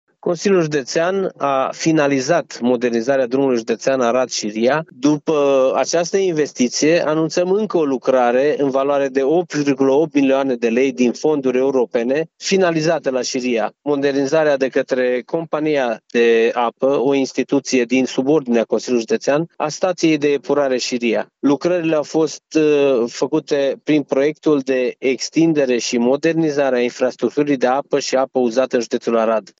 Este vorba despre o investiție realizată în cadrul proiectului de extindere şi modernizare a infrastructurii de apă din judeţ, spune președintele Consiliului Județean Arad, Iustin Cionca.